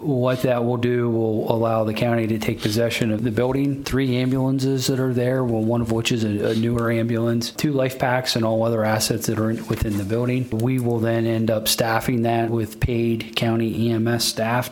The Allegany County Commissioners have finalized the long-awaited takeover of the George’s Creek Ambulance Service.  County Administrator Jason Bennett told the commissioners the Memorandum of Understanding gives the county control of the failed volunteer station…